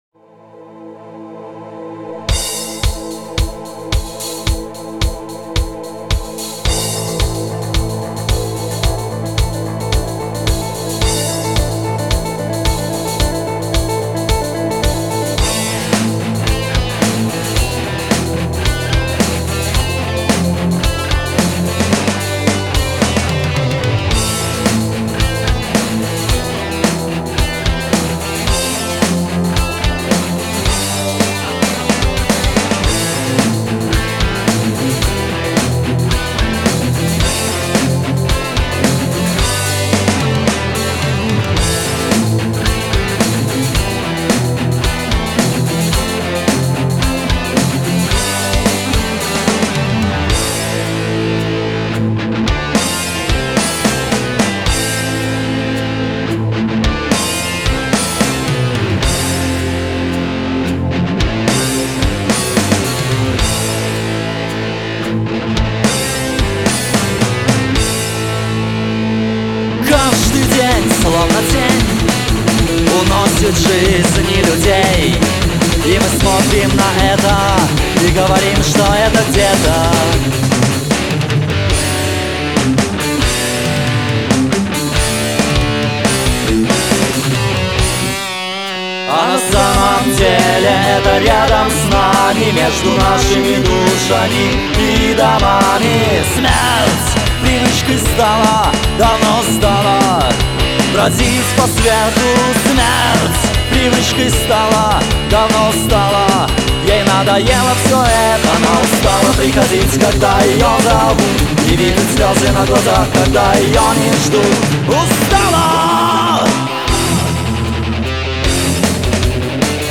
Альбом записан в стиле heavy metal, тексты на русском языке.
ударные
бас
ритм-гитара
соло-гитара, бэк-вокал
вокал